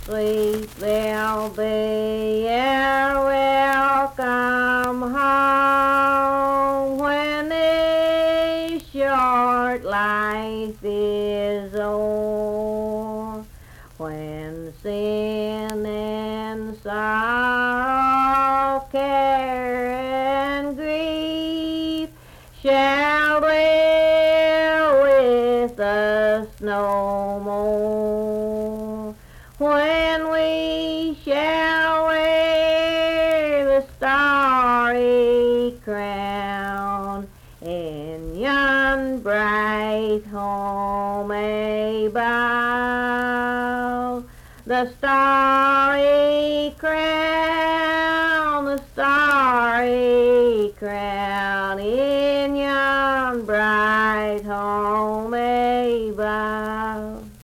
Unaccompanied vocal music
Hymns and Spiritual Music
Voice (sung)
Nicholas County (W. Va.), Richwood (W. Va.)